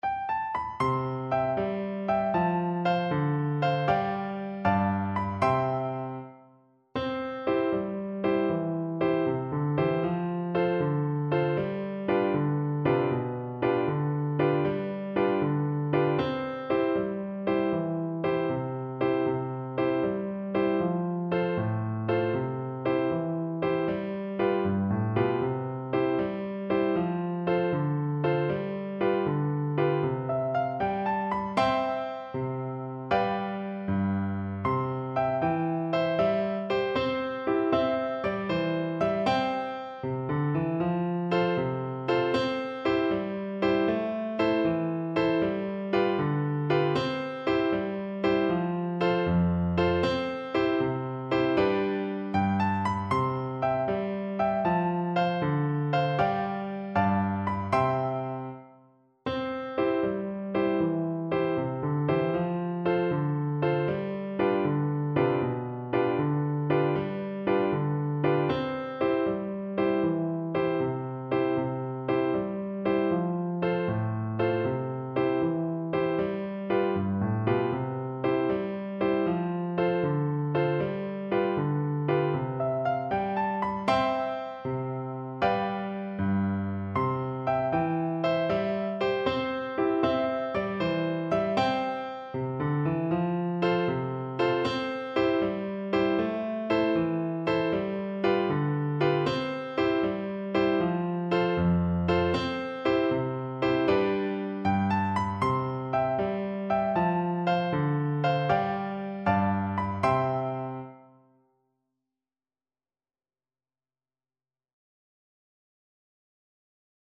C major (Sounding Pitch) (View more C major Music for Flute )
6/8 (View more 6/8 Music)
Traditional (View more Traditional Flute Music)